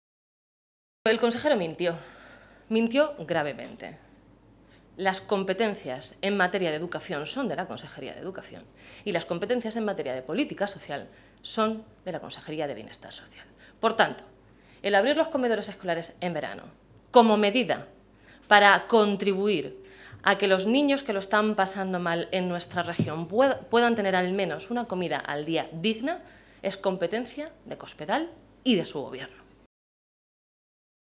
Blanca Fernández, portavoz de Educación del Grupo Parlamentario Socialista
Cortes de audio de la rueda de prensa